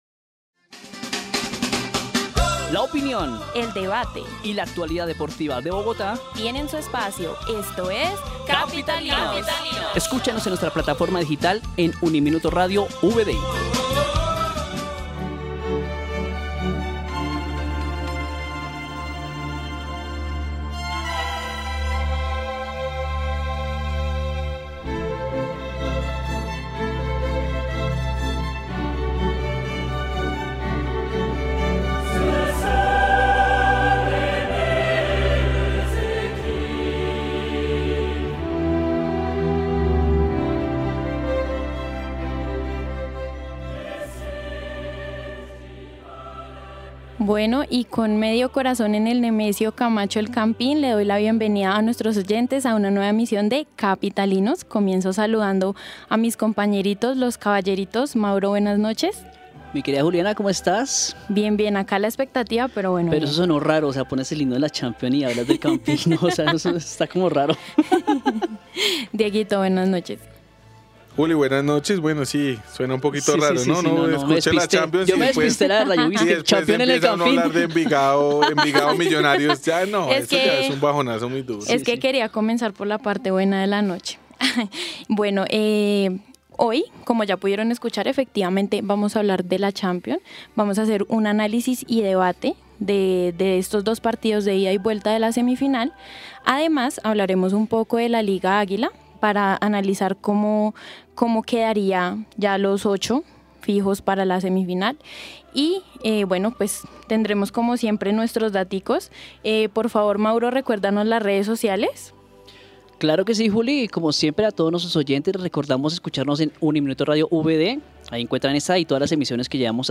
En esta emisión de Capitalinos, dedicaremos un espacio especial para hablar de fútbol; haremos un debate y análisis de la actualidad de la semifinal de la Champions League, así como lo que está pasando en la recta final del todos contra todos de la liga águila.